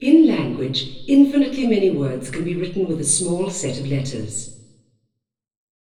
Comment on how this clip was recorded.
I made an auralization (the link is below) using the center pair, with the listening location set at the location of the deepest dip, so we can see if it’s an issue. Auralization using the center pair – Have a listen! SM96-CTR-SINGLE-LSTNR-FOR-INTFRNCE.wav